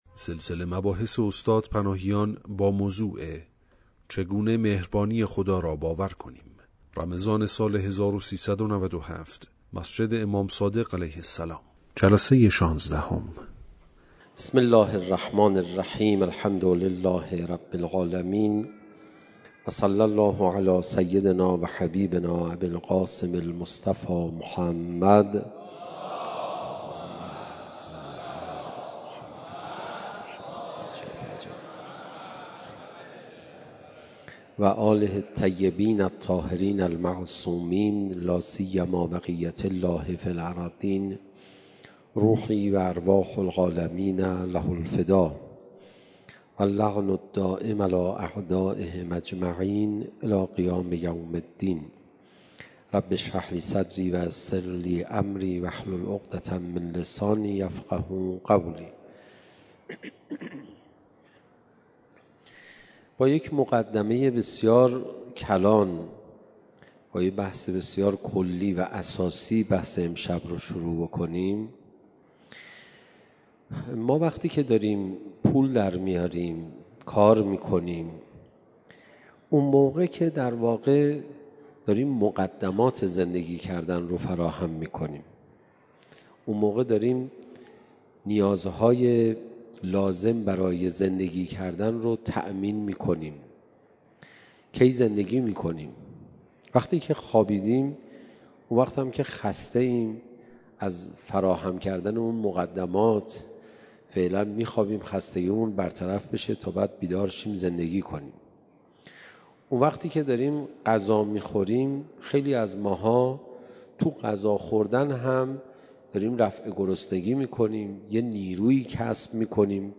شب 16 رمضان 97 - مسجد امام صادق (ع) - چگونه مهربانی خدا را باور کنیم؟